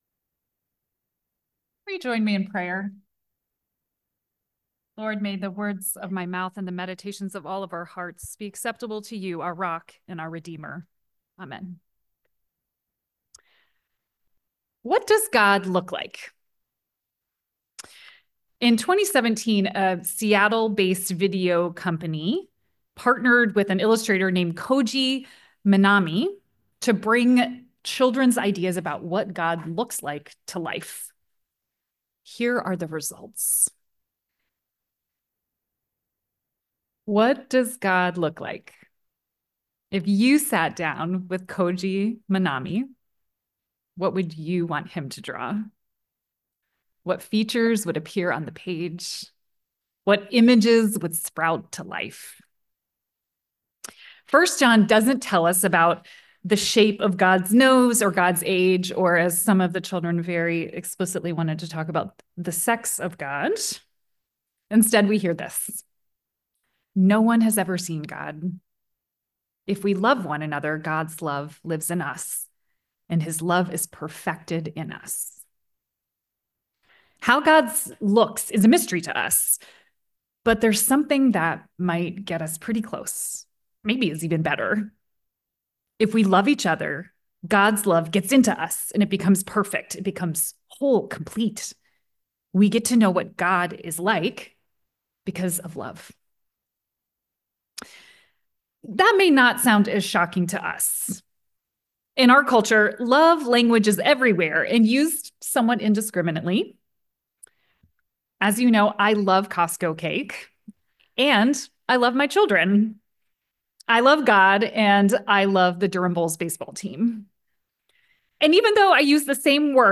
Scripture: 1 John4:7-21 On this Anabaptist World Fellowship Sunday